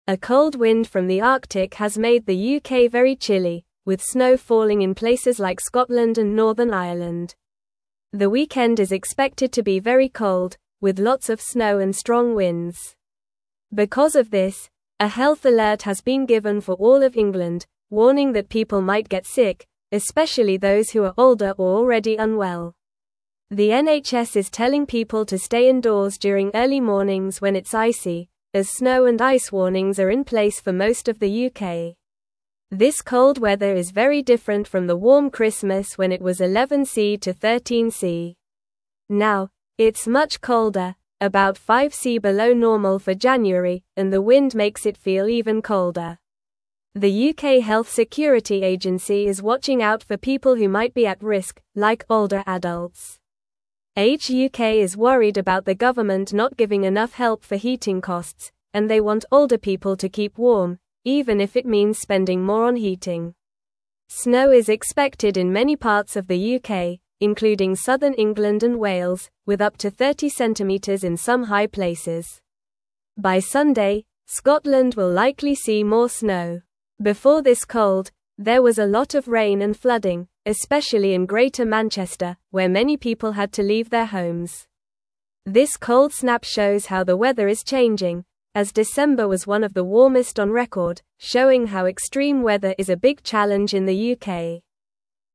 Normal
English-Newsroom-Lower-Intermediate-NORMAL-Reading-Big-Cold-Wind-Brings-Snow-to-the-UK.mp3